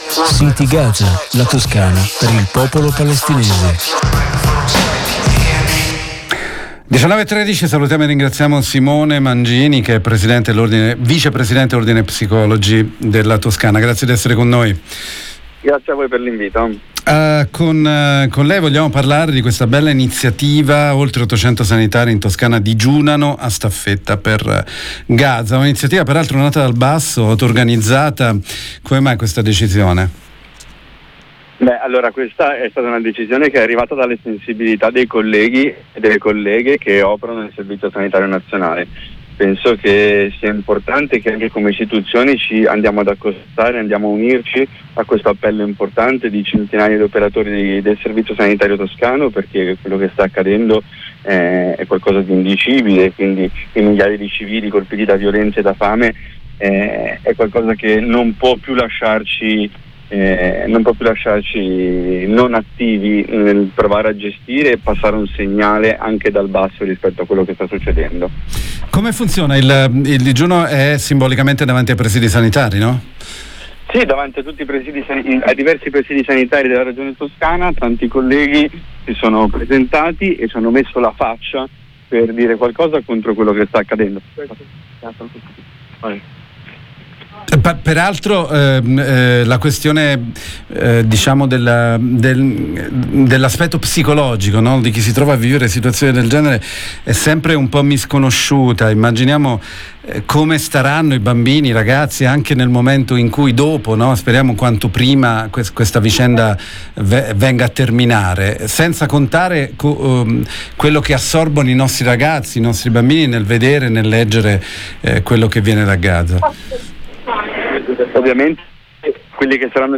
gazaDal 29 luglio centinaia di operatori hanno iniziato un digiuno  davanti ad oltre 40 sedi ospedaliere e territoriali, distretti sanitari, pronto soccorso, «per protestare contro il genocidio in corso a Gaza». intervista